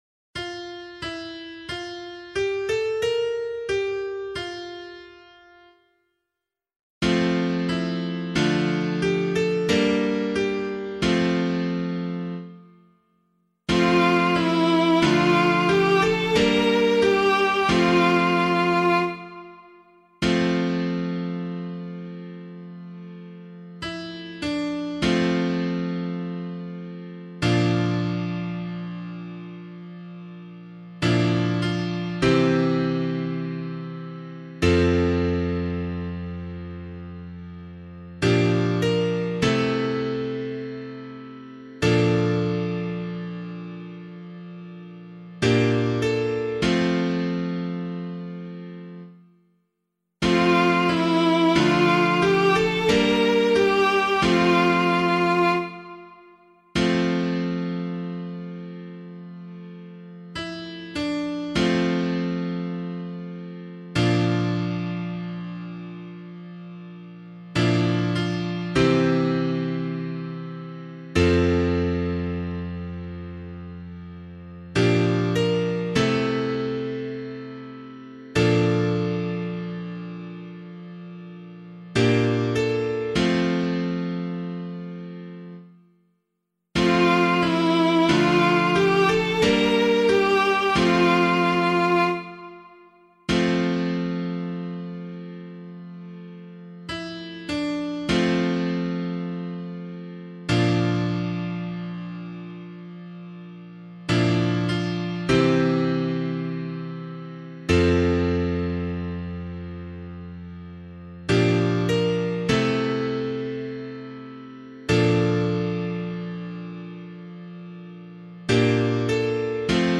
016 Lent 4 Psalm C [APC - LiturgyShare + Meinrad 6] - piano.mp3